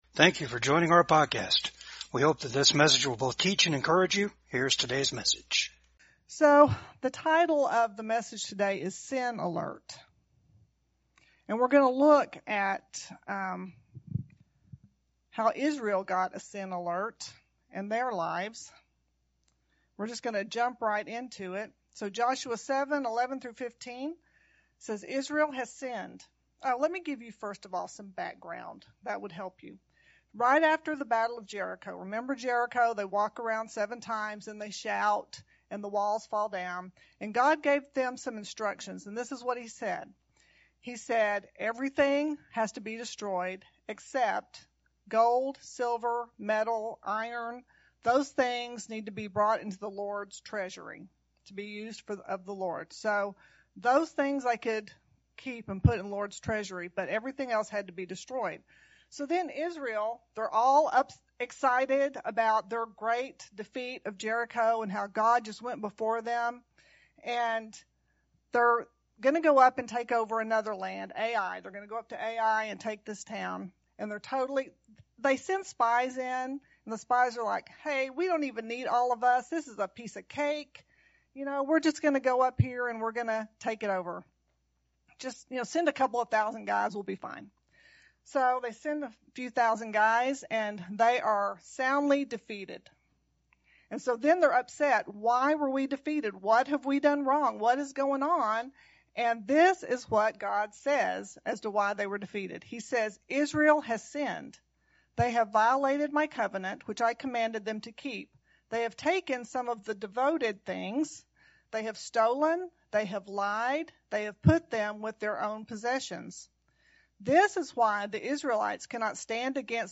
19-25 Service Type: VCAG SUNDAY SERVICE IS THERE ANY SIN IN YOUR LIFE?